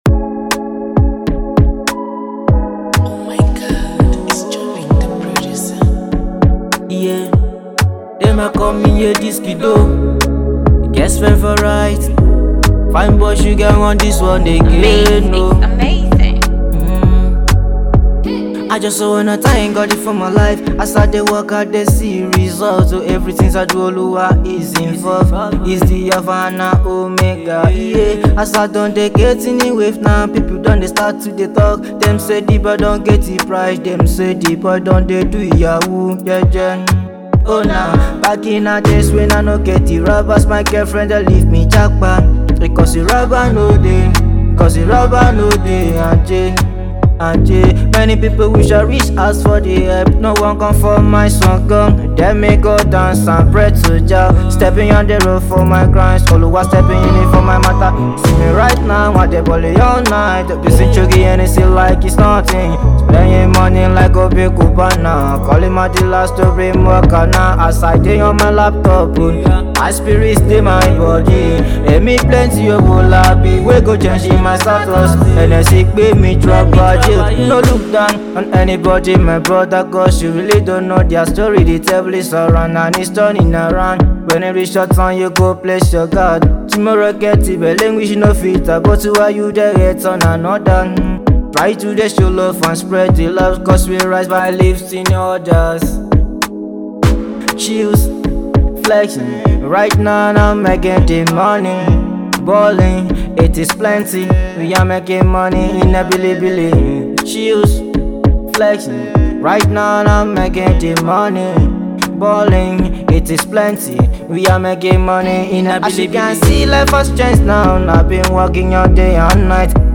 Am Into afro beat.
The beat? Loaded. The vibe? Charged.